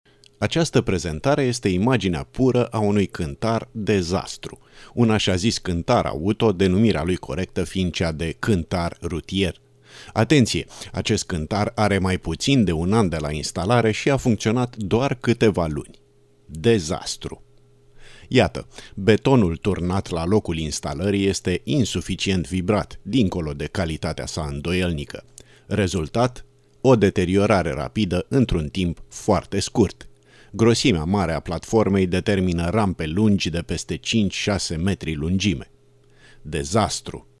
Experienced Voice Over, Romanian native
Sprechprobe: eLearning (Muttersprache):